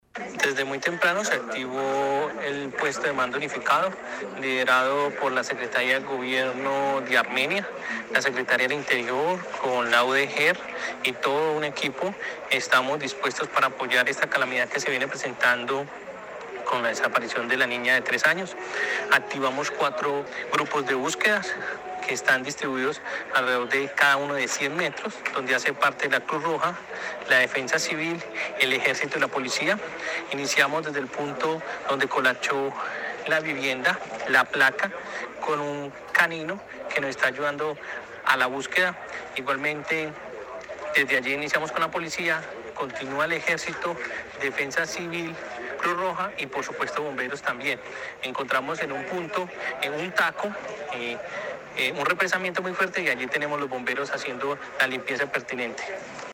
Audio: Secretario del Interior. Jaime Andrés Pérez Cotrino. Gobierno del Quindío
Jaime-Andres-Perez-Cotrino-y-desapricion-de-nina-en-sector-Las-Veraneras-de-Armenia.mp3